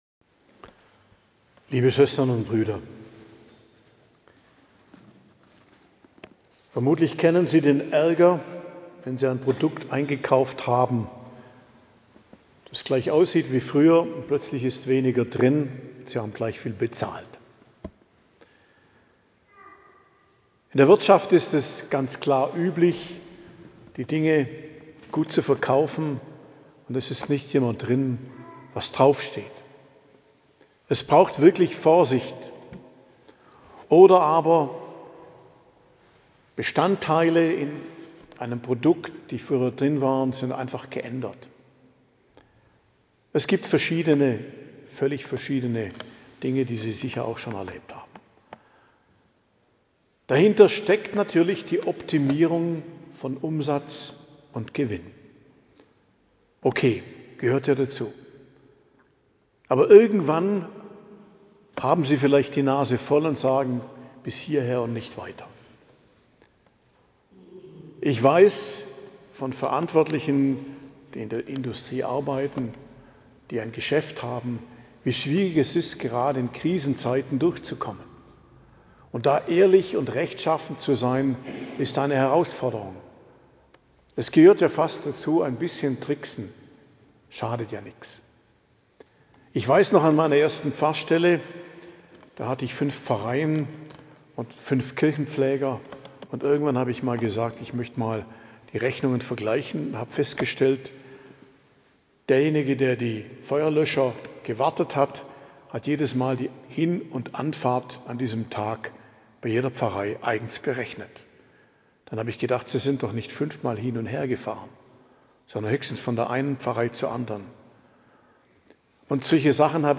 Predigt zum 25. Sonntag i.J., 21.09.2025 ~ Geistliches Zentrum Kloster Heiligkreuztal Podcast